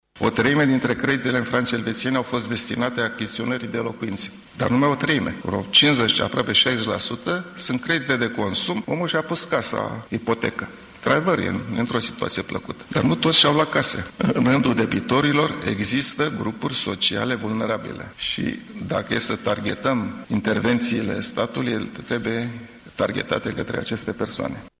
Banca Naţională a apreciat drept nerealiste propunerile de convertire a creditelor în franci elveţieni la nivelul de acum câţiva ani, iar guvernatorul Mugur Isărescu a declarat că o astfel de conversie ar produce pierderi de aproape 6 miliarde de lei: